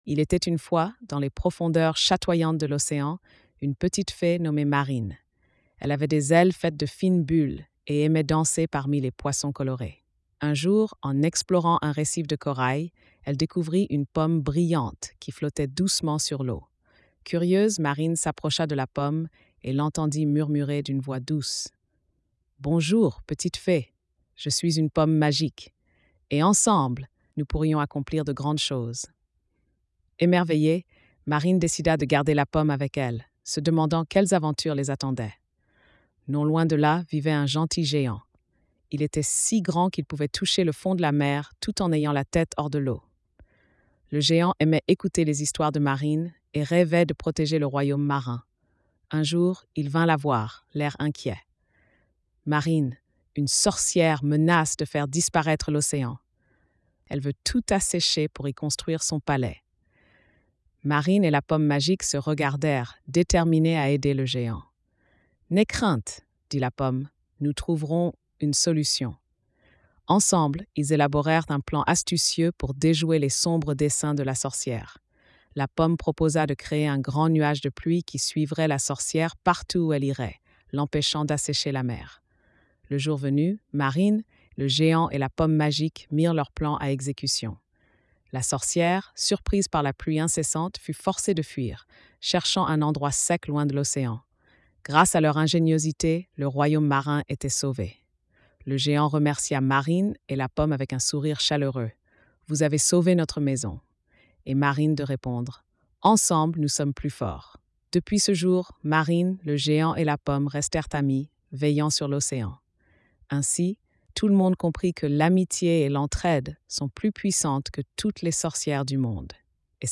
Marine et la Pomme Magique - Conte de fée
🎧 Lecture audio générée par IA